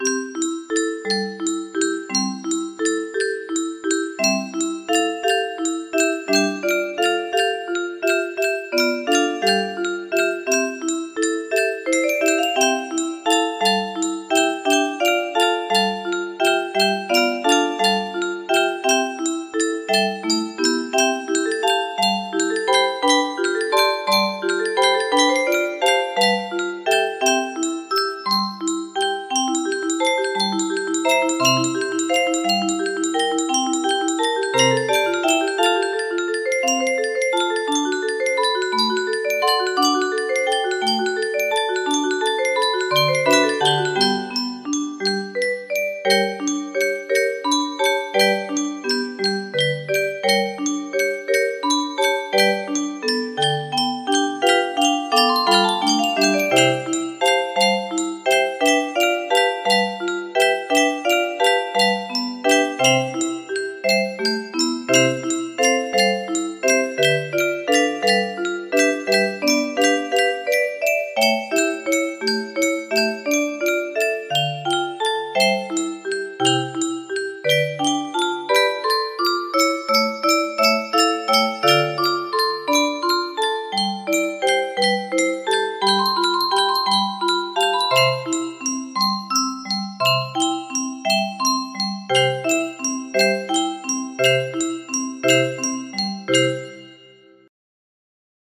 Barcarolle (Cuentos de Hoffmann) de Offenbach music box melody
Cute and sweet arrangement of this beautiful piece from Offenbach.